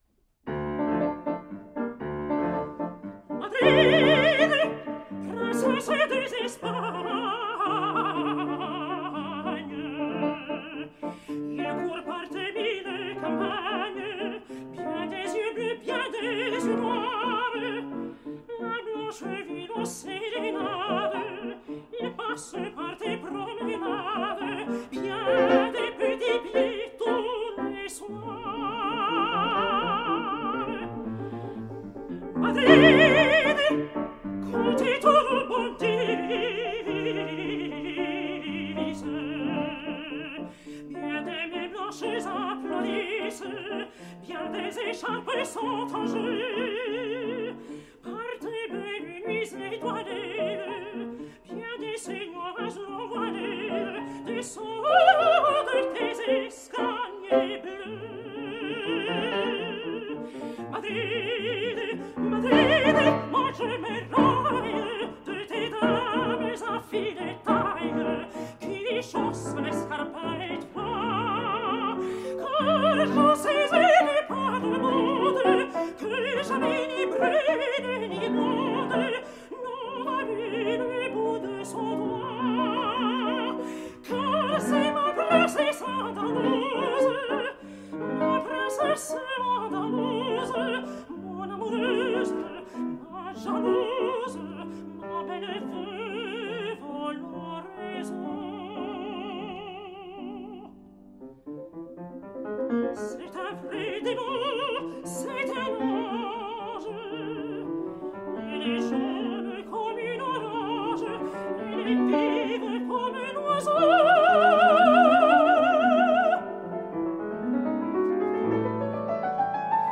Espagnolades françaises pour piano (1830-1942).